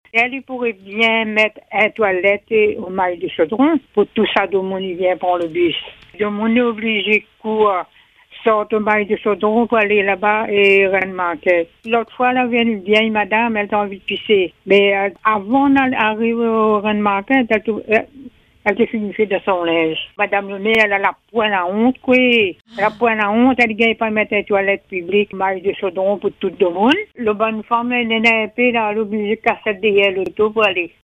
Au Mail du Chaudron, une usagère de la station de bus dénonce l’absence totale de toilettes publiques.